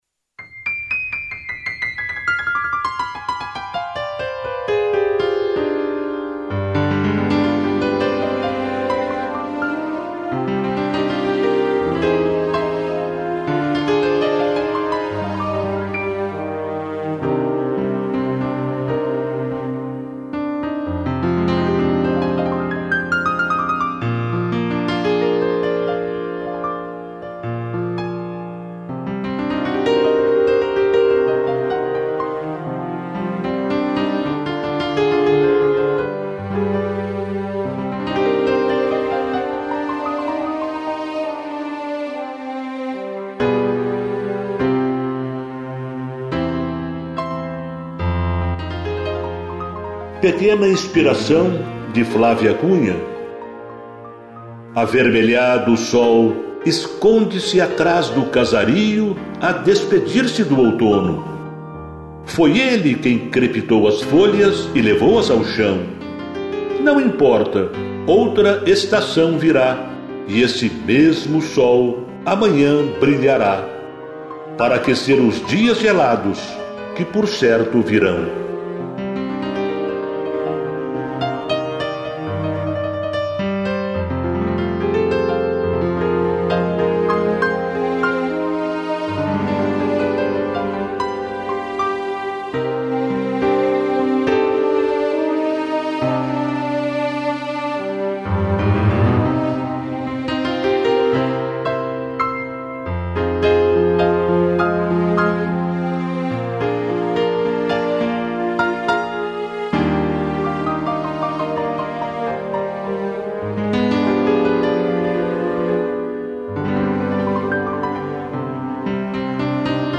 2 pianos e tutti